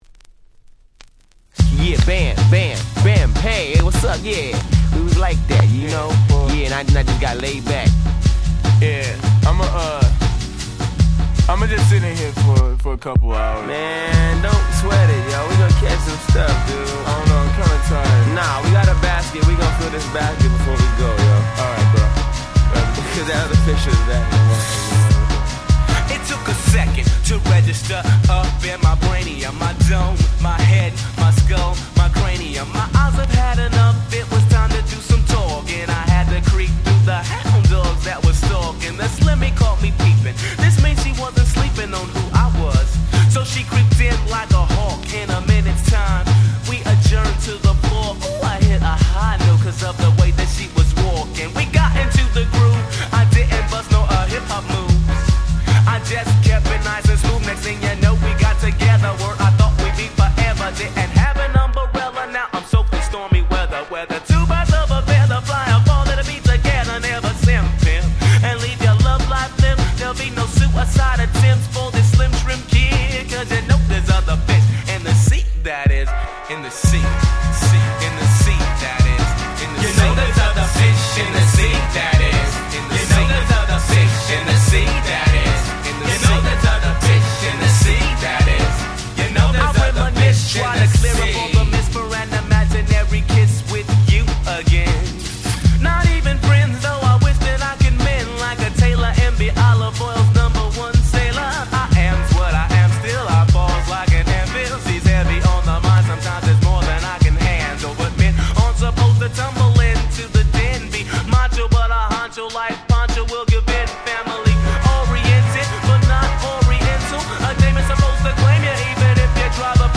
93' Smash Hit Hip Hop !!
まったりとしたBeatがとんでもなくチルな90’s Hip Hop Classics !!